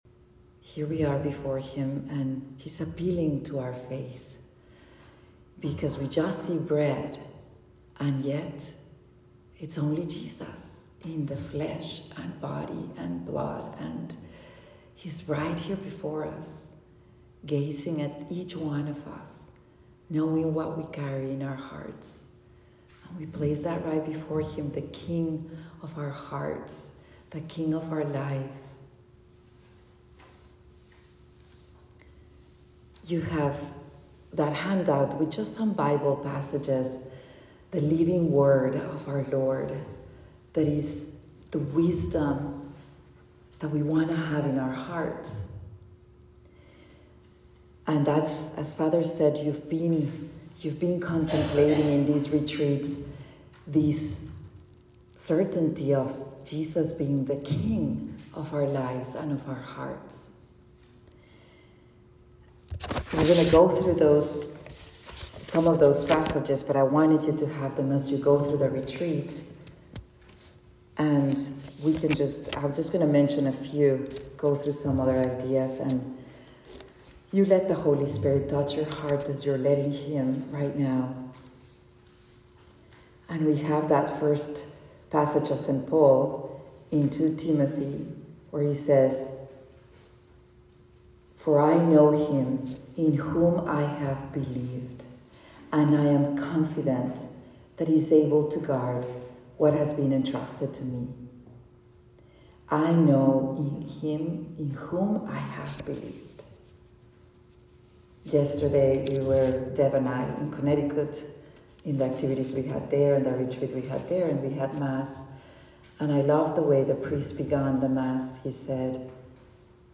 Meditation
at the New Jersey Morning of Reflection Retreat